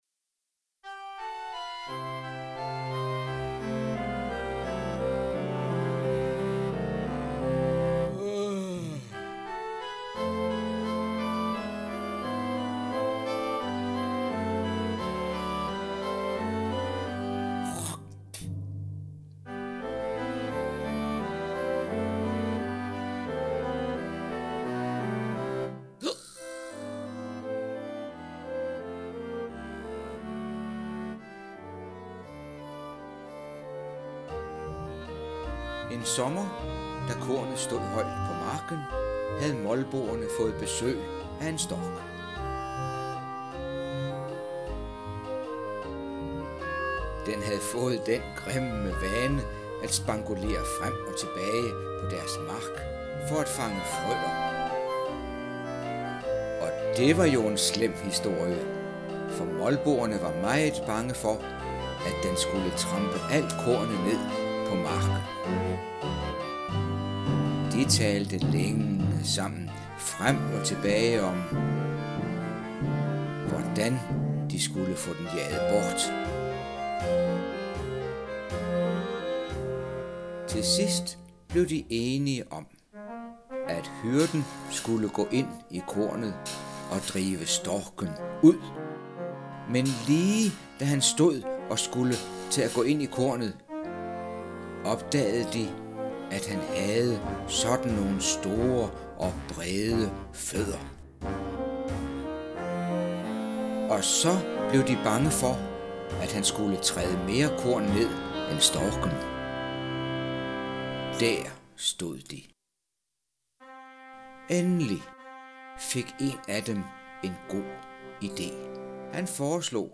(spillet af min PC)